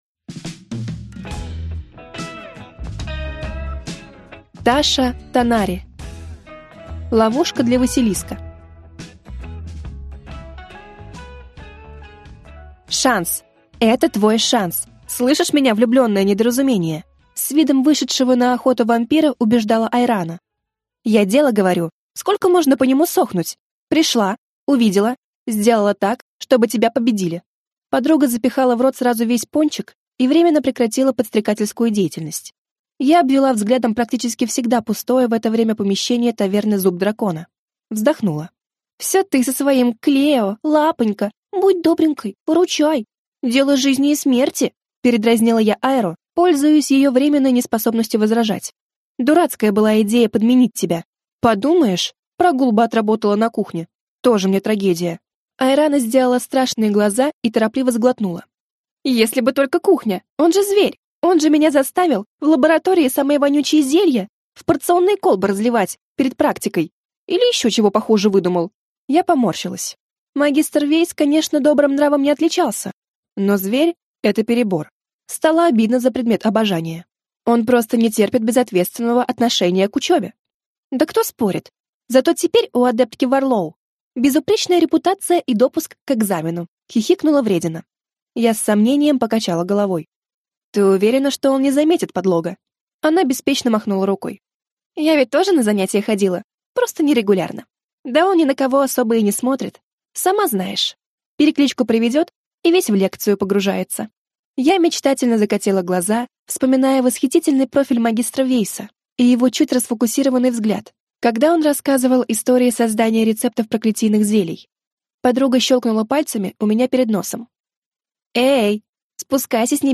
Аудиокнига Ловушка для василиска | Библиотека аудиокниг